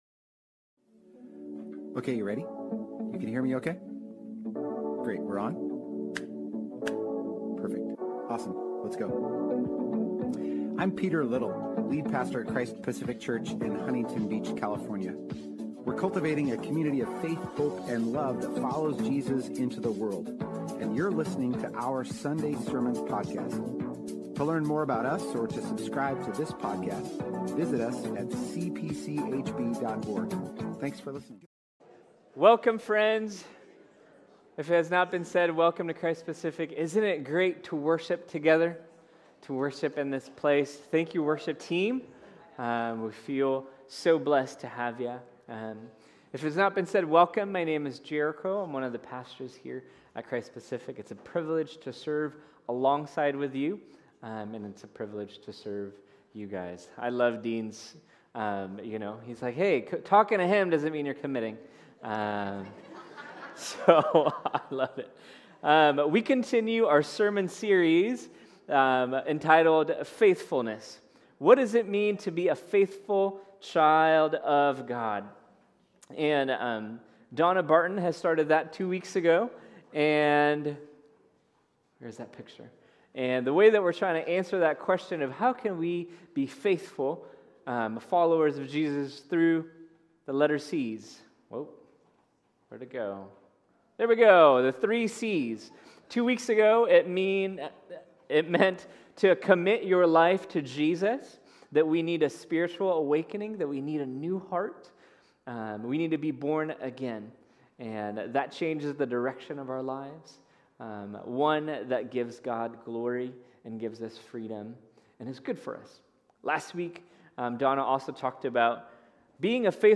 Join us today as we continue our current sermon series, ‘Faithfulness: Committed, Called, Connected’.